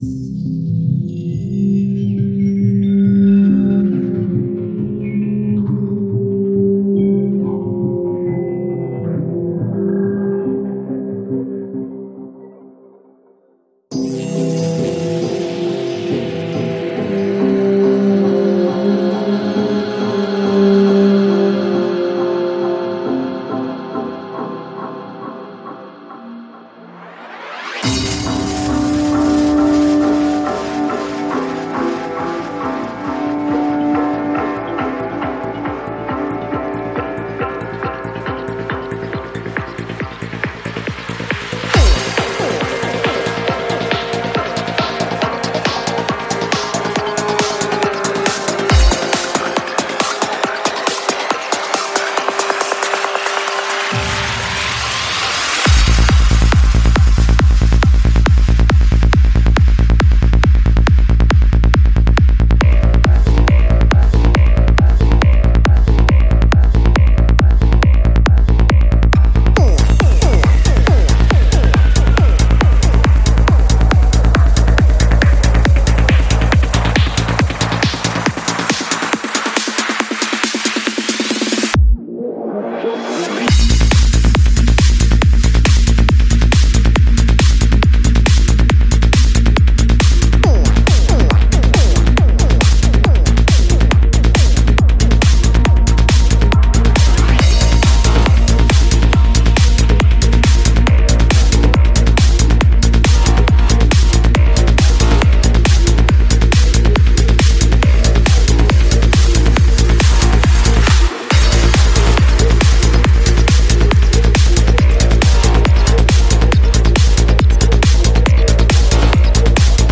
Жанр: Electro, Dance, Trance